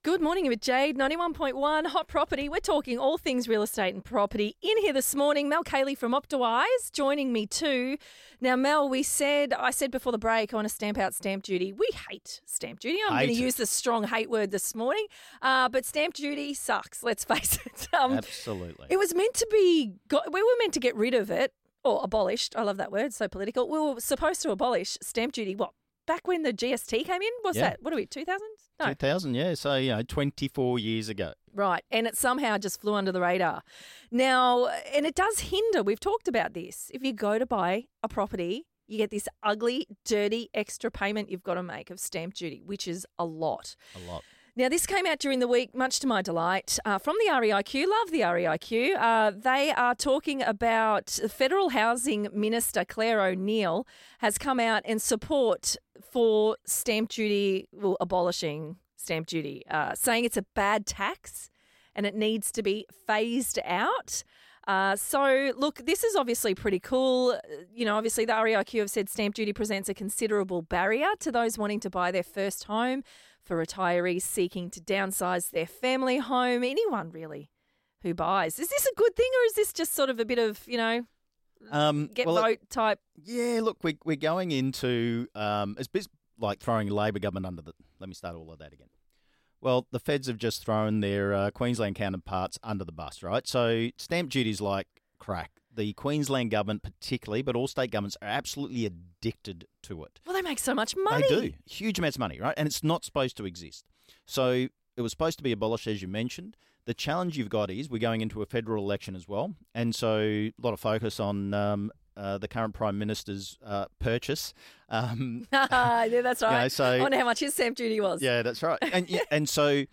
In his recent Hot Property interview